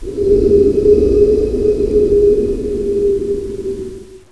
1 channel
wind2.wav